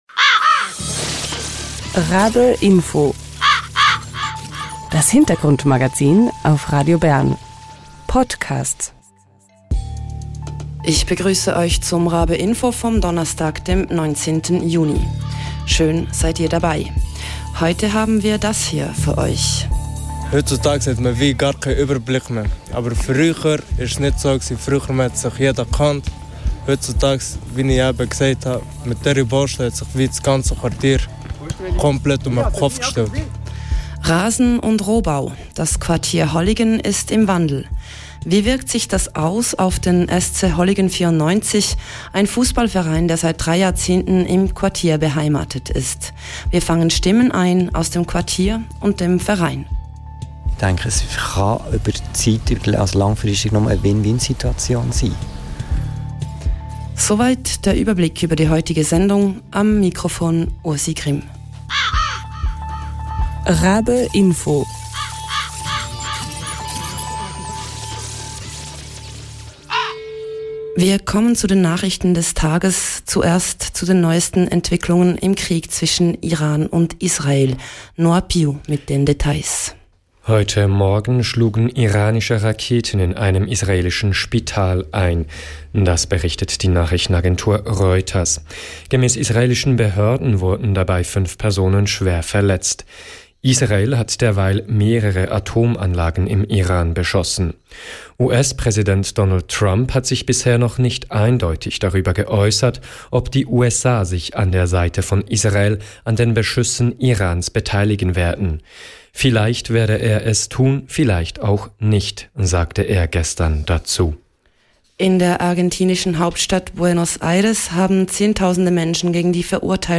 In der Reportage begegnen wir den Holliger:innen.